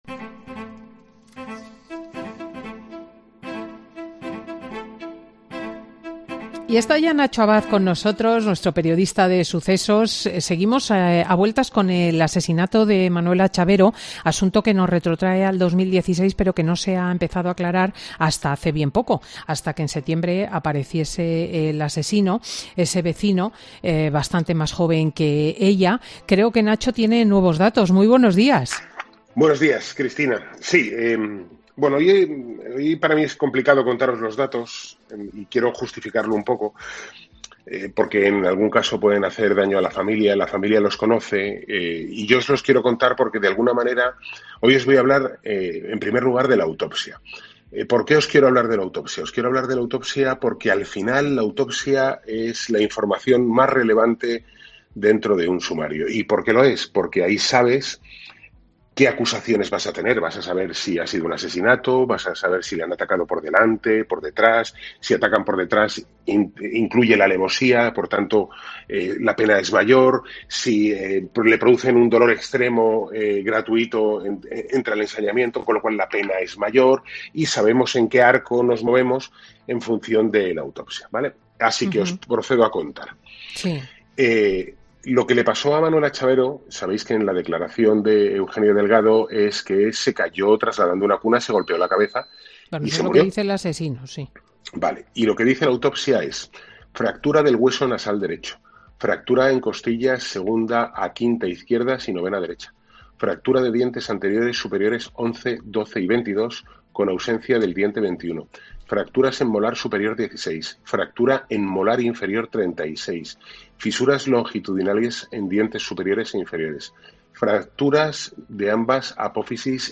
AUDIO: El periodista experto en sucesos comenta en Fin de Semana con Cristina las novedades de este caso tan mediático